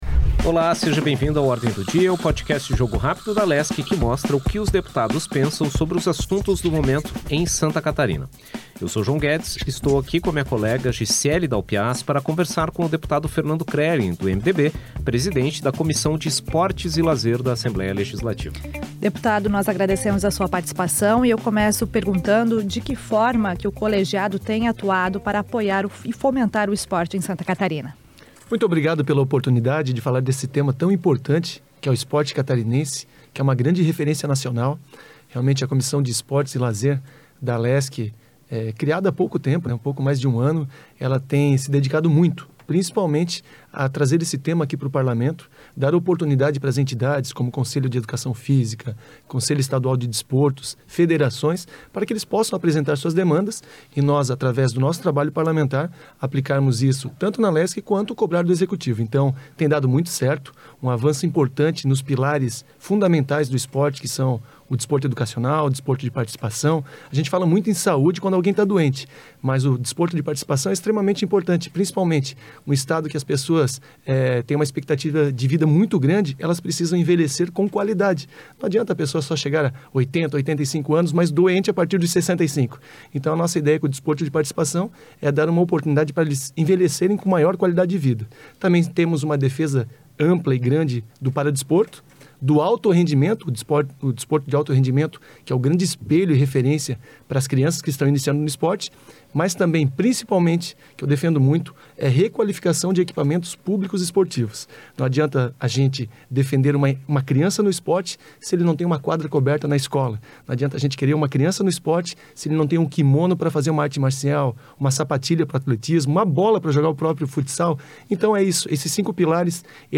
Os desafios para o apoio ao esporte em Santa Catarina são o tema do bate-papo com o deputado Fernando Krelling (MDB), presidente da Comissão de Esportes e Lazer da Assembleia Legislativa.